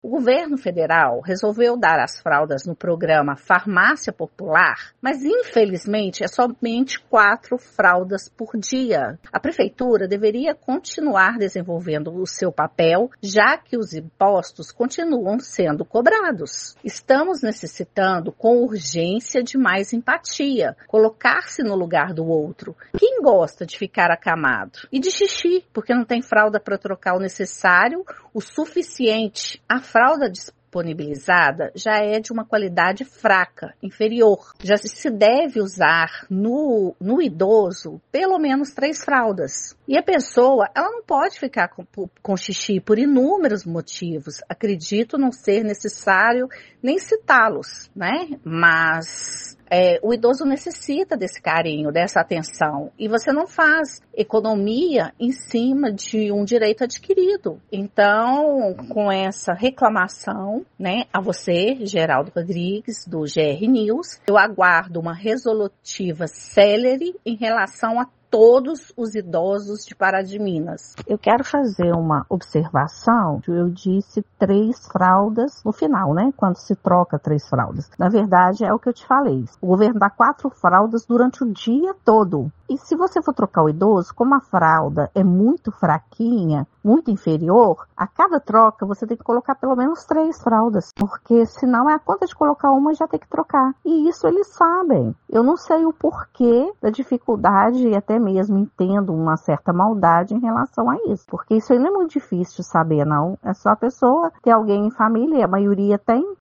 Filha de pessoa acamada